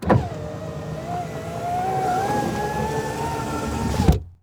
windowup.wav